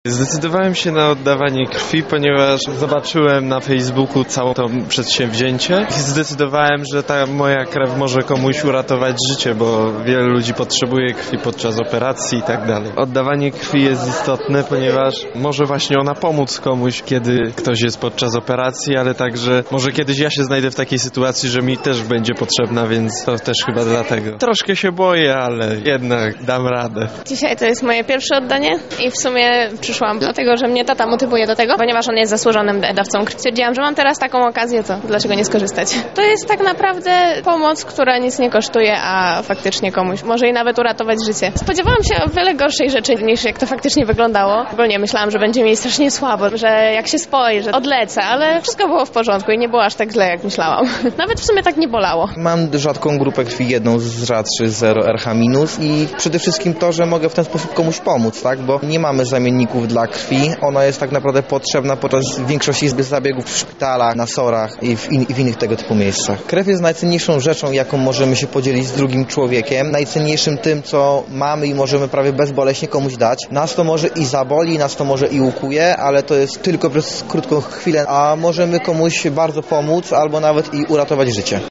sonda
sonda.mp3